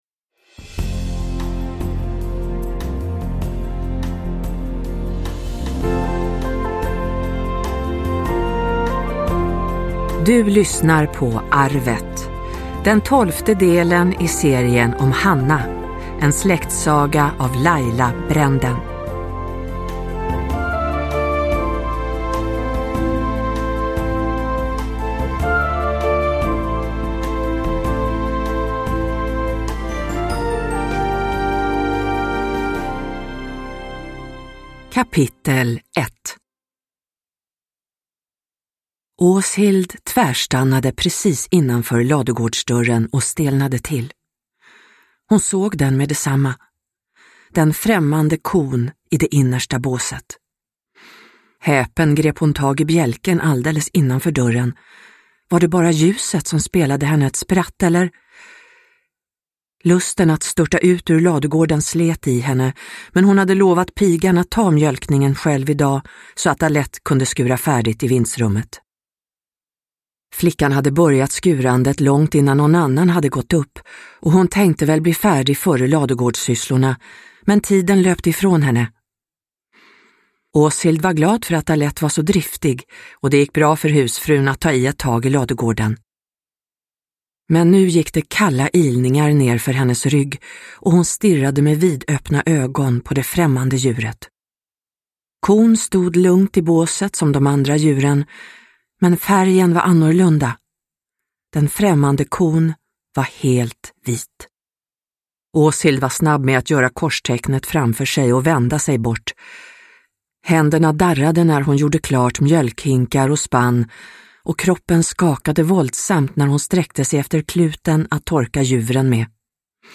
Arvet – Ljudbok – Laddas ner